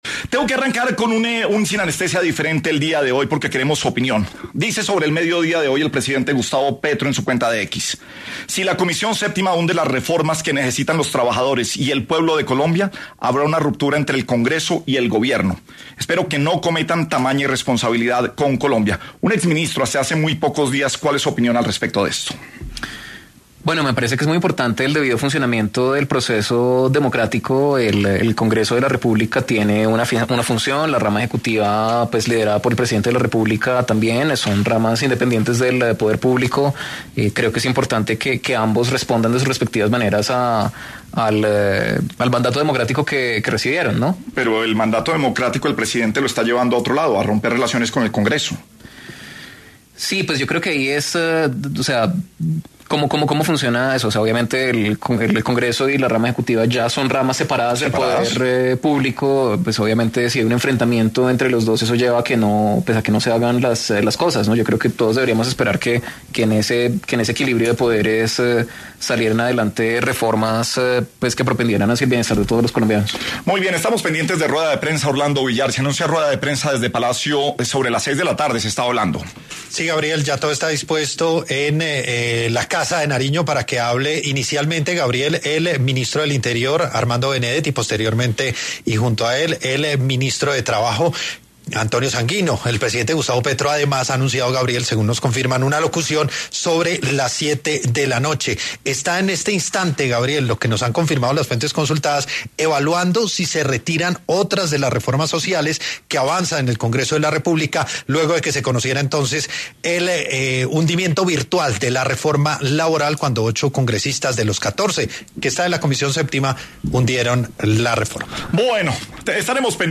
El exministro de Comercio, Industria y Turismo, expresó en ‘Sin Anestesia’ de Caracol Radio su visión sobre el equilibrio de poderes en el país
Reyes estuvo en los micrófonos de ‘Sin Anestesia’, de Caracol Radio y Red+ Noticias, en donde compartió su visión con respecto al funcionamiento del Estado en este tema coyuntural, en donde, además, confesó cómo se dio su renuncia, admitiendo que hubo presiones por parte de congresistas.